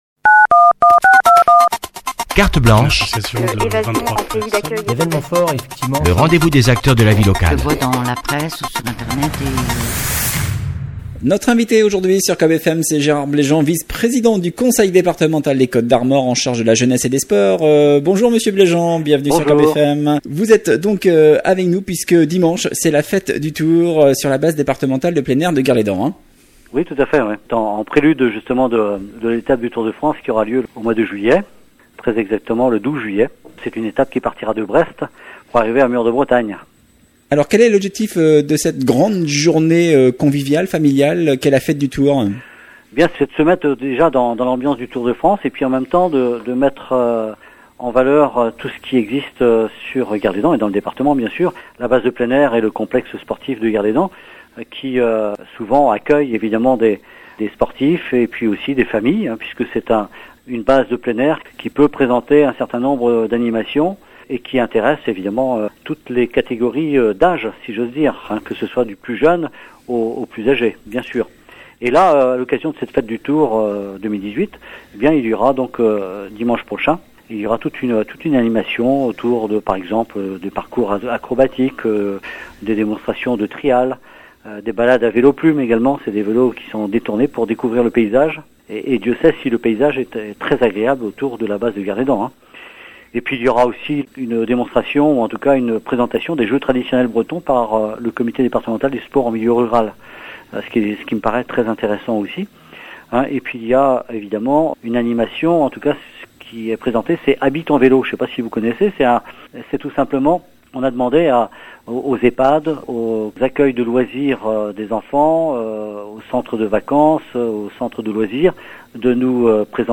Avant l’arrivée des coureurs du Tour de France le 12 juillet à Mûr-de-Bretagne, Le Département vous invite à participer à cette grande journée familiale, comme nous l’affirme Gérard Blégean, vice-Président du Conseil départemental des Côtes d’Armor en charge de la jeunesse et des sports